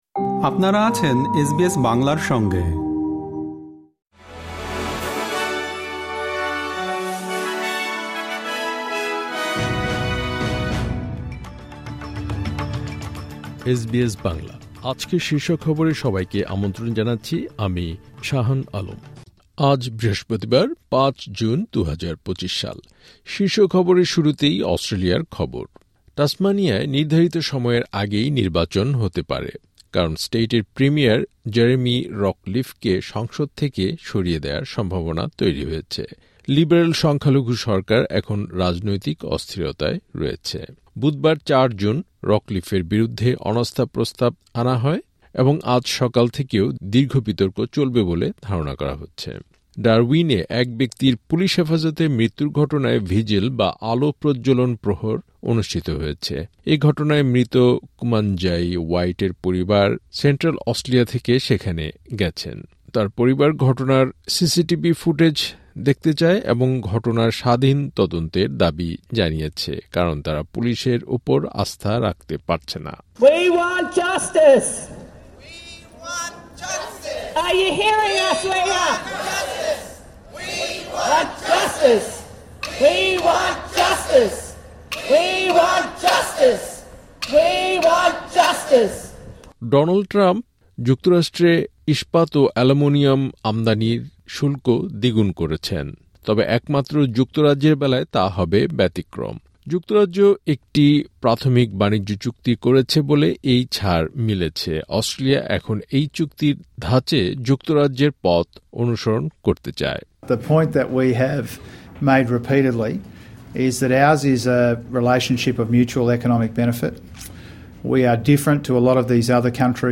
এসবিএস বাংলা শীর্ষ খবর: ৫ জুন, ২০২৫